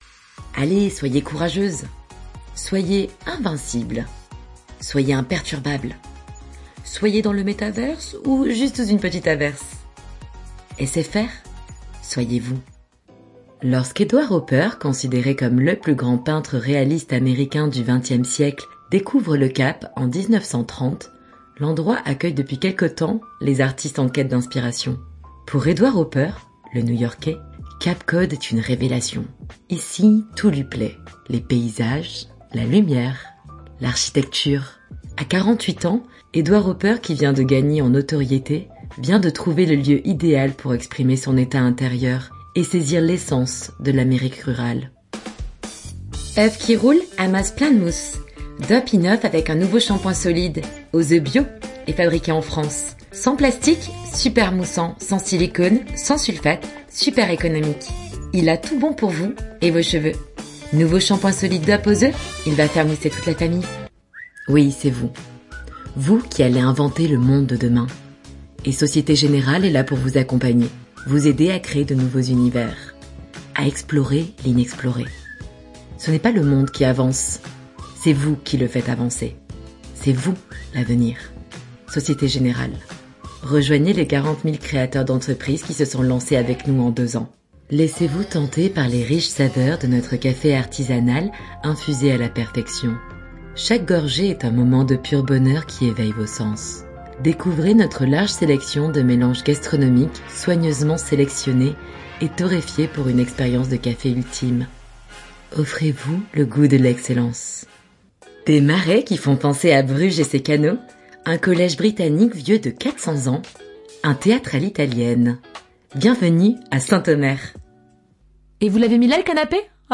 Maquette bande-son
- Basse